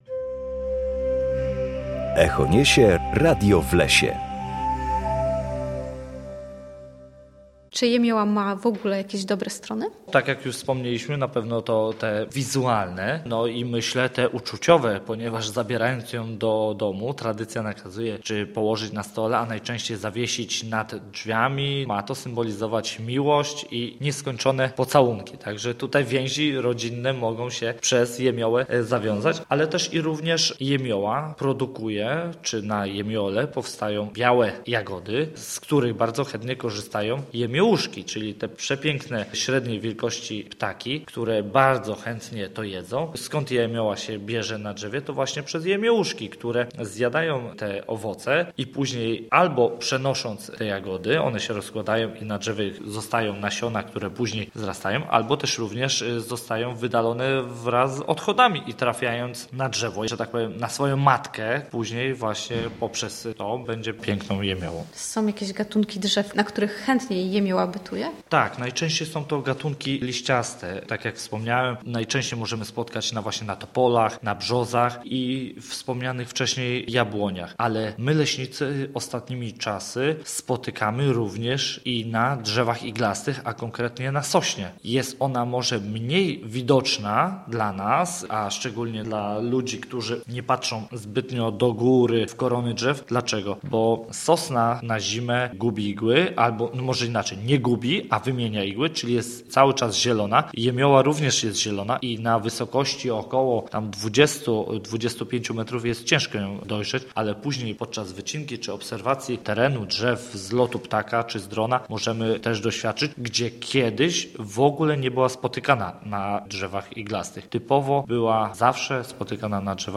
Posłuchaj audycji o naturze roślin
W każdą środę o godzinie 7:20 na antenie Studia Słupsk rozmawiamy o naturze i sprawach z nią związanych.